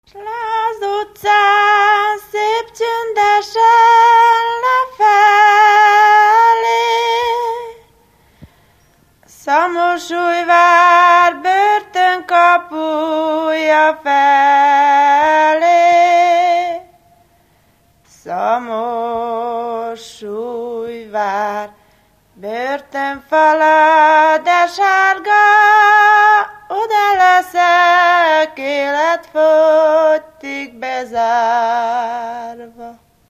Erdély - Szolnok-Doboka vm. - Feketelak
ének
Stílus: 6. Duda-kanász mulattató stílus
Kadencia: 8 (5) 7 1